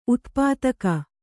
♪ utpātka